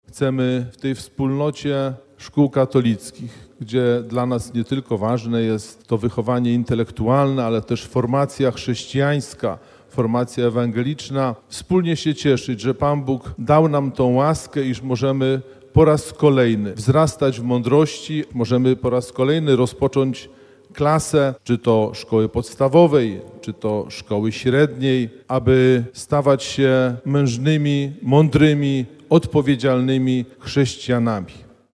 Mszą Św. w Katedrze Wrocławskiej rozpoczęła się inauguracja roku szkolnego 2025/2026 dla placówek katolickich Archidiecezji Wrocławskiej.